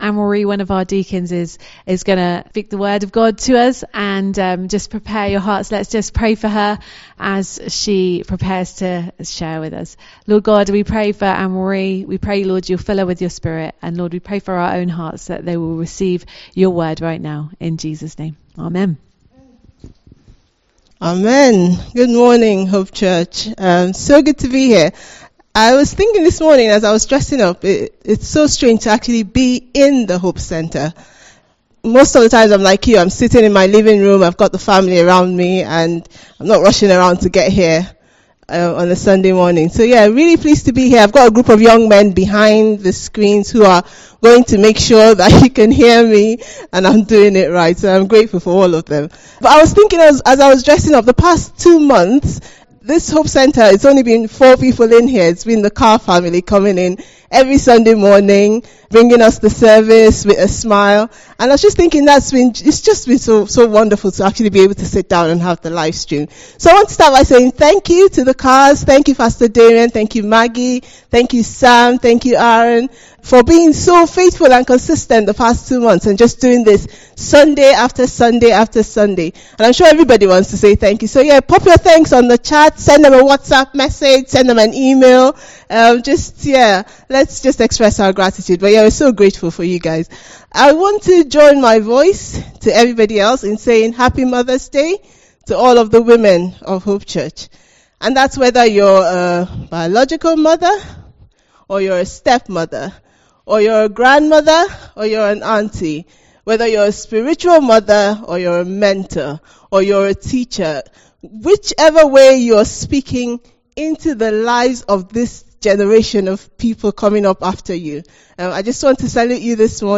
In this teaching as we recall the changes in the last year and look forward to even more changes ahead, we reflect on the unchanging nature of our God, the importance of pressing into Jesus and the need to be fervent in our devotion to Him.